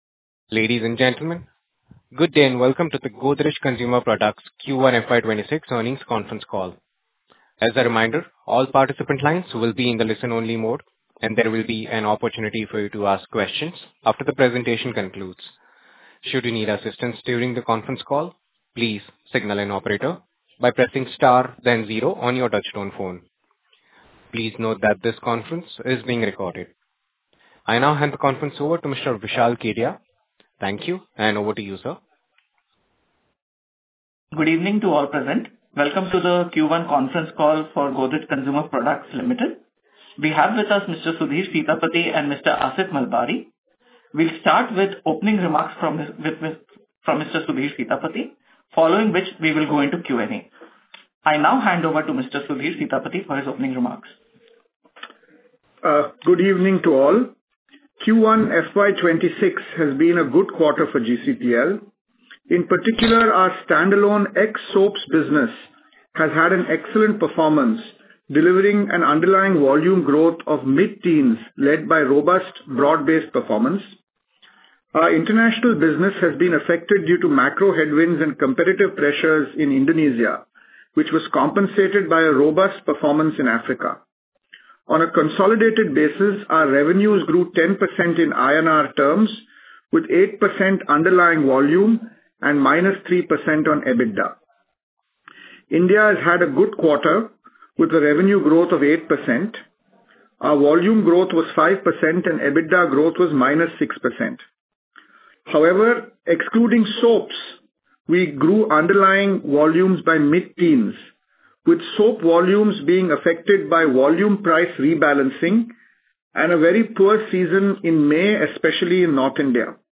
Playback_of_Q1FY26_Earnings_Call.mp3